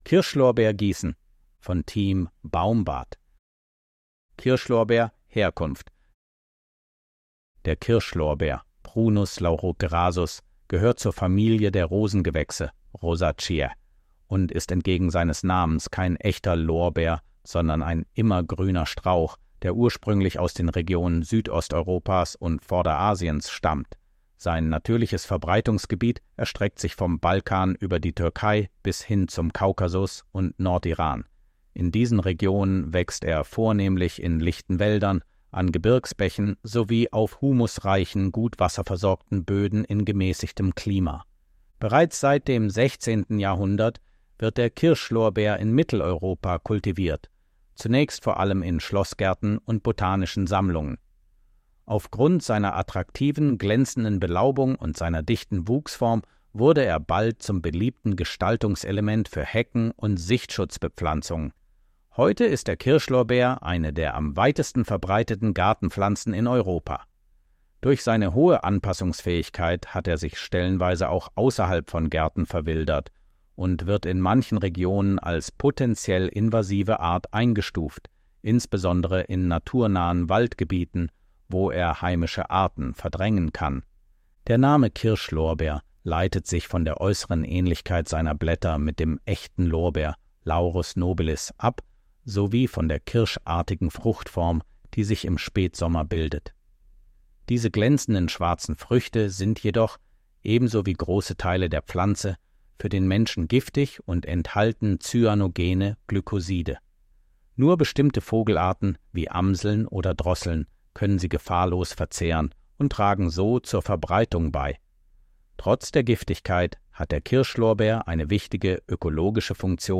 Artikel vorlesen